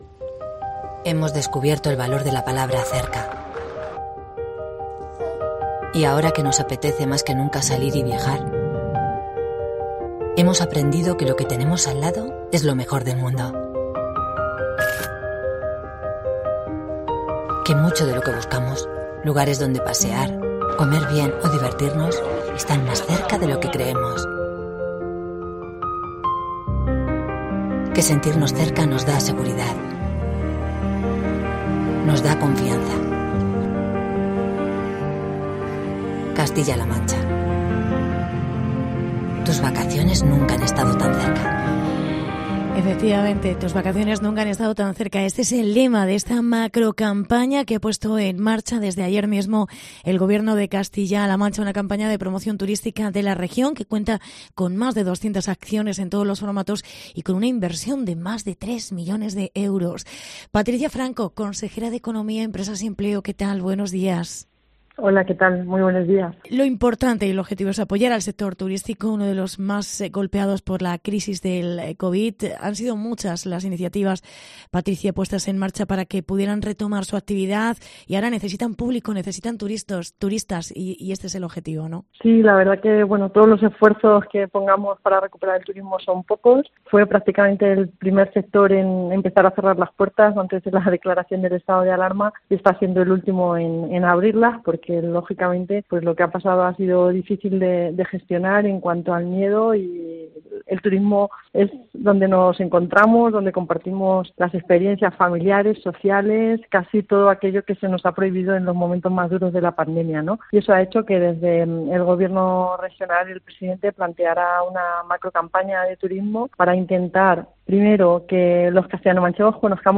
Entrevista con Patricia Franco. Consejera de Economía, Empresas y Empleo